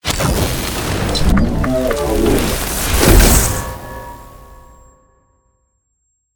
teleport.ogg